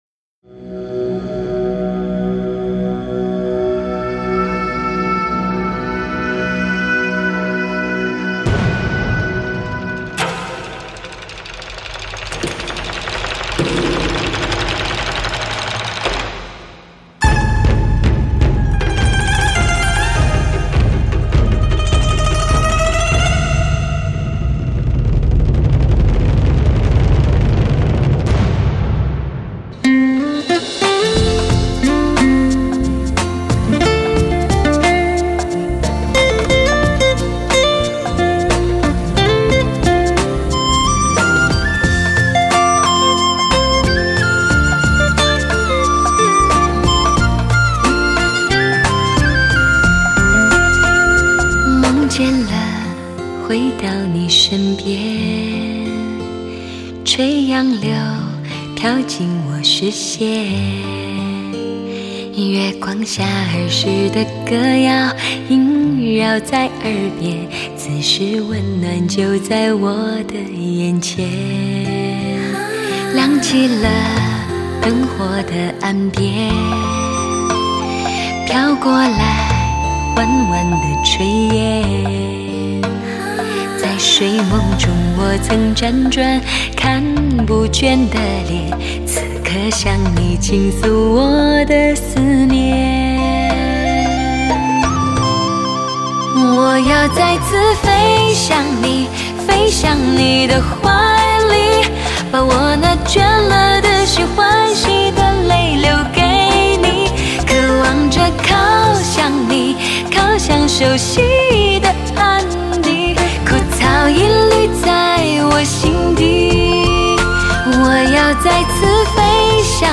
幽幽淡雅的歌声，变身成为知己爱人，为你倾听她的内心。